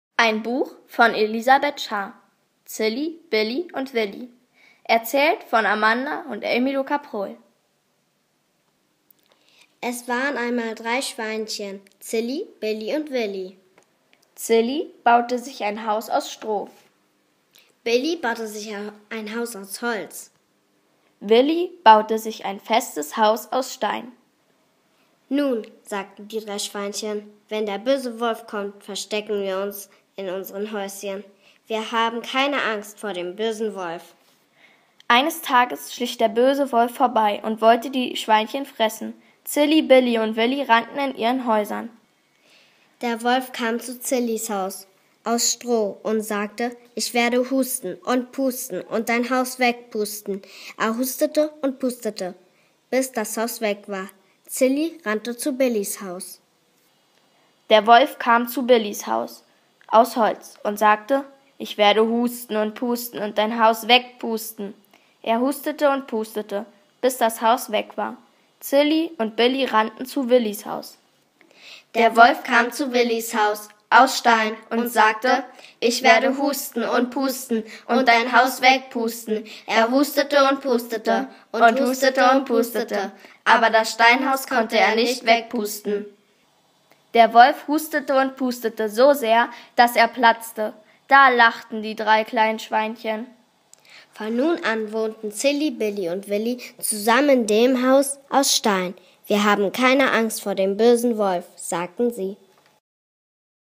Die Drei Kleinen Schweinchen Hörspiel Mit Bildern.mp3